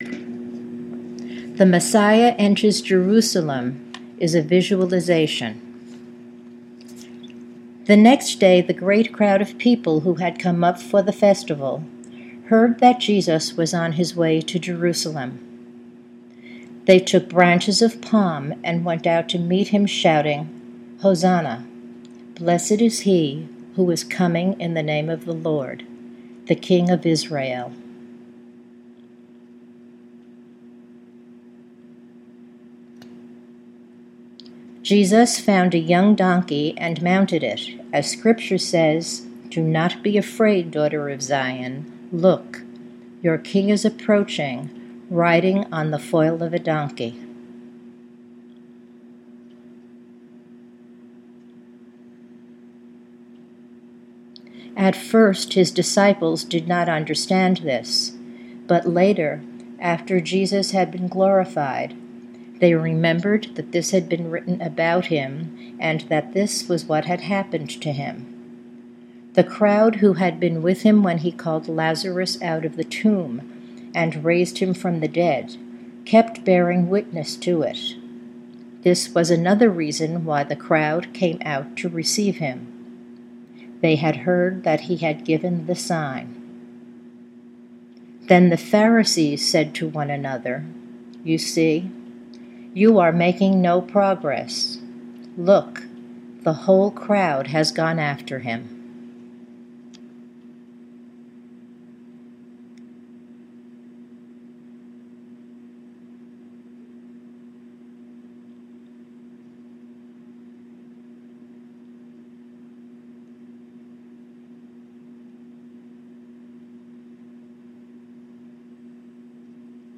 You’ll hear a beep after a short time to remind you to open your eyes.